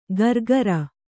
♪ gargara